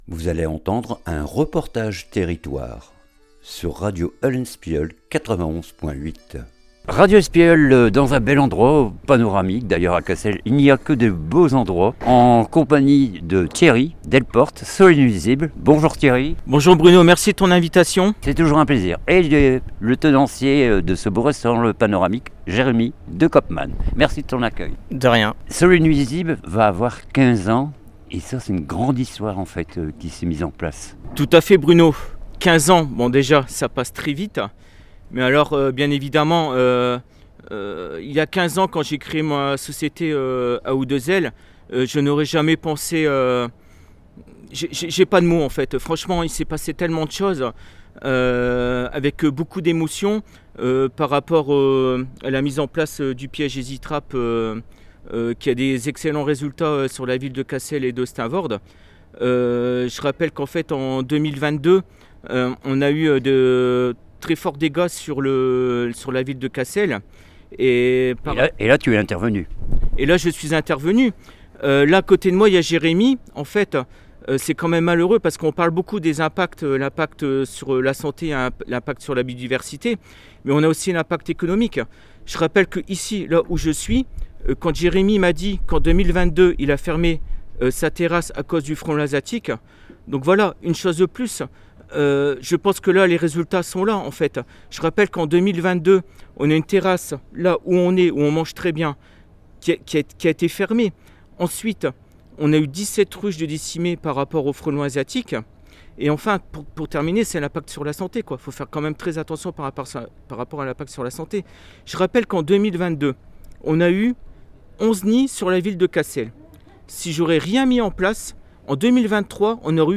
REPORTAGE TERRITOIRE SOLUNUISIBLES CONFERENCE 28 MARS